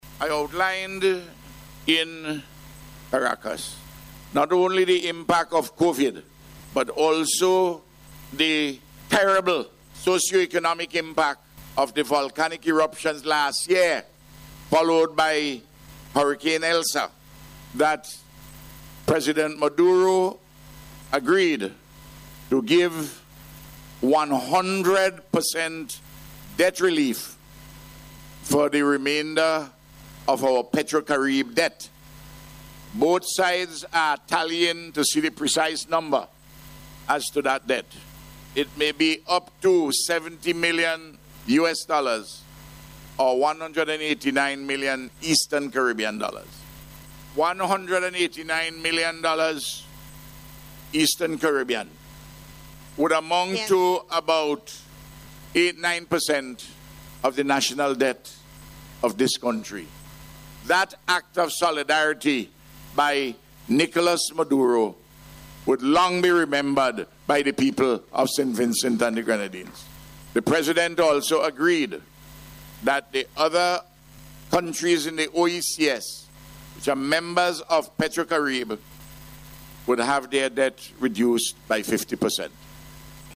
This was announced by Prime Minister Dr. Ralph Gonsalves during a ceremony at the Argyle International Airport yesterday, to welcome the inaugural flight of Conviasa Airline from Venezuela.